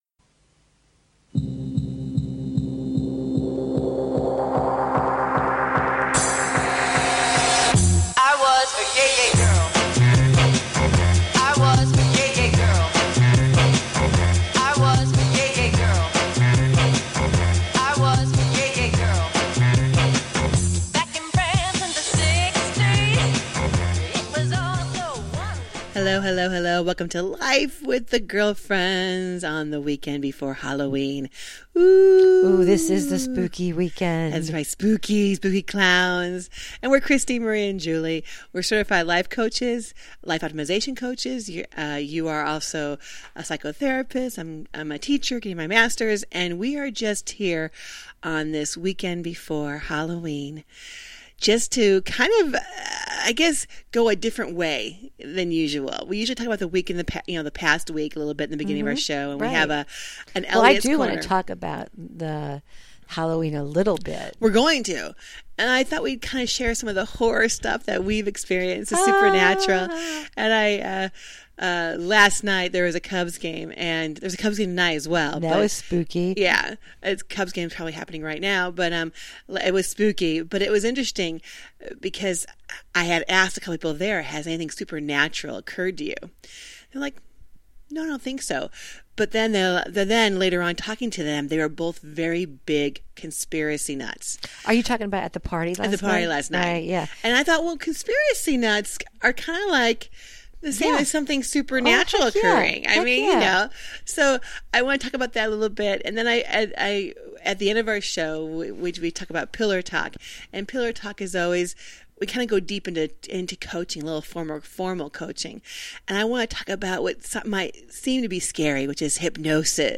Talk Show Episode
And join the girlfriends up close and personal for some daily chat that’s humorous, wholesome, and heartfelt.